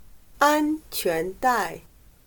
安全带/ānquán dài/Correas de fijación y protección de carrocerías para asientos de aviones y vehículos de motor